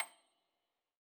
53l-pno30-C7.wav